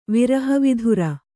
♪ viraha vidhura